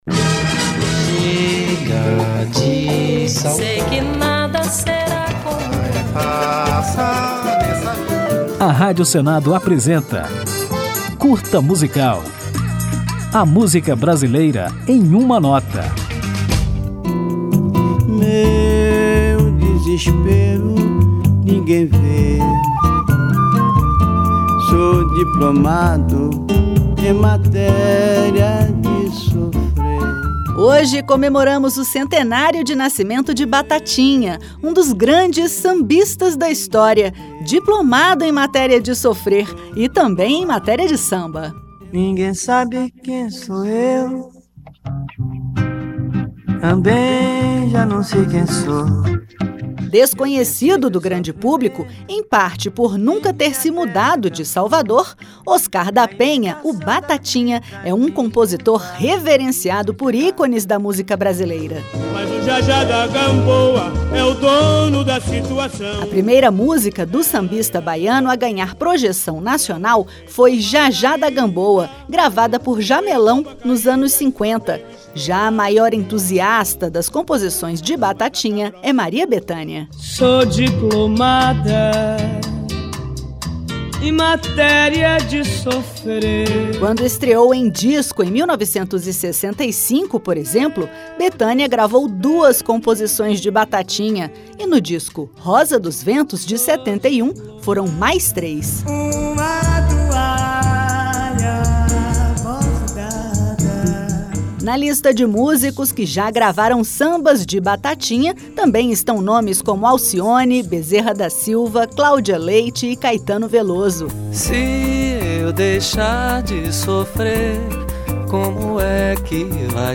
Confira tudo sobre o artista nesta homenagem do Curta Musical, que, ao final, toca a música Direito de Sambar, um dos clássicos de Batatinha.
Samba